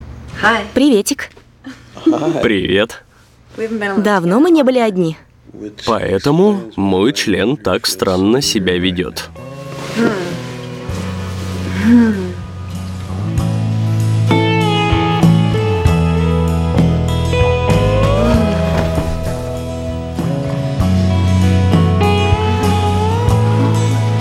• Качество: 320, Stereo
диалог
голосовые